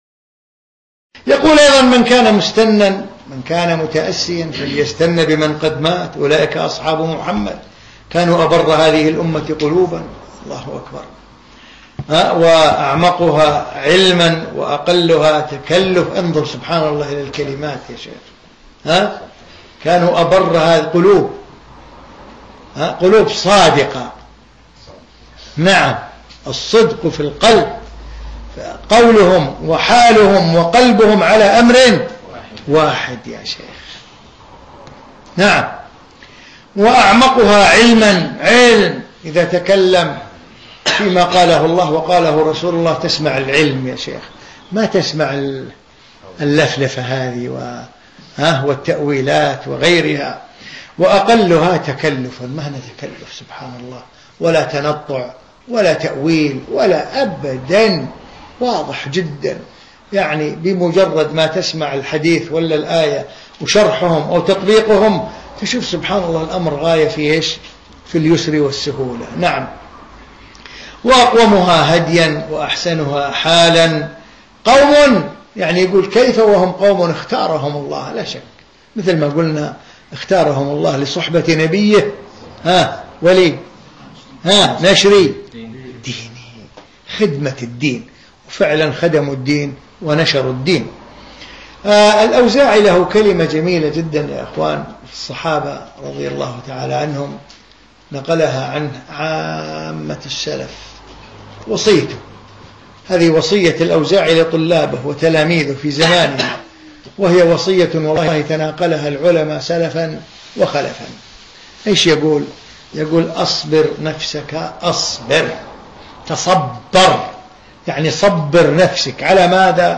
في مسجد الصحابة السلفي في مدينة برمنجهام البريطانية في 20 شوال 1435